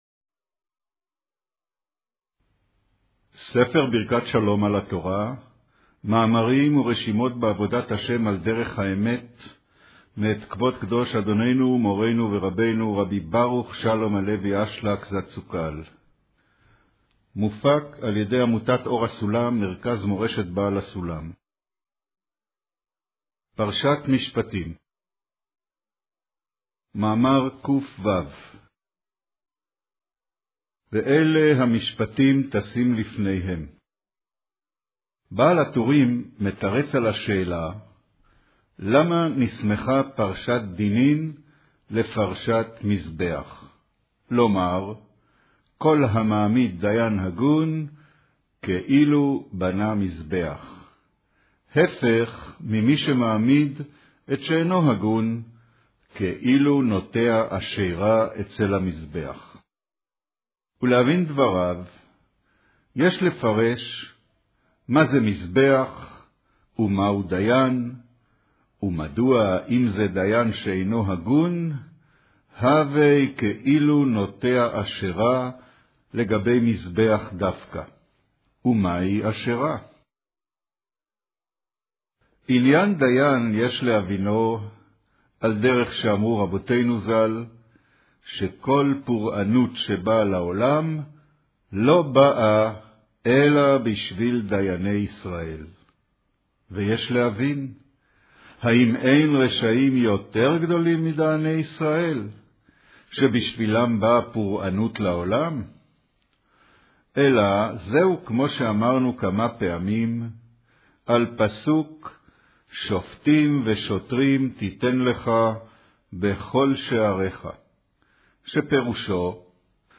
אודיו - קריינות פרשת משפטים, מאמר ואלה המשפטים תשים לפניהם